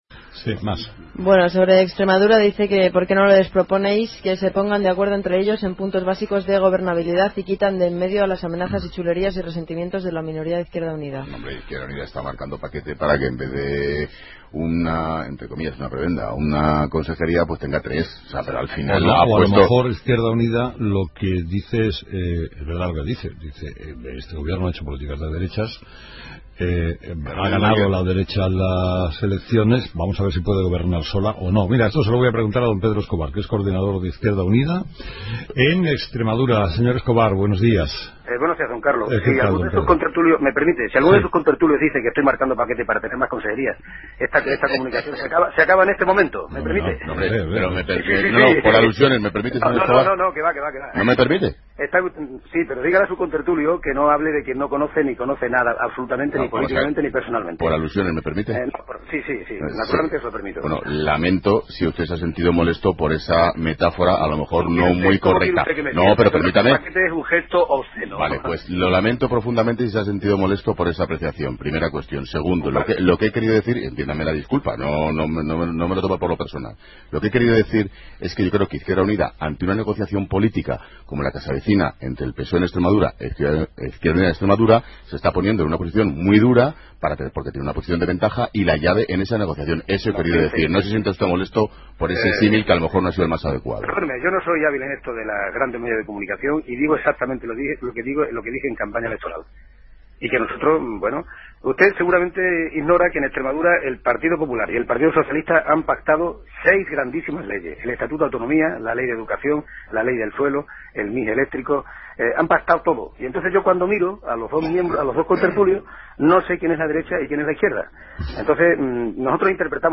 Entrevista con Pedro Escobar